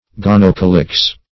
Search Result for " gonocalyx" : The Collaborative International Dictionary of English v.0.48: Gonocalyx \Gon`o*ca"lyx\, n. [Gr.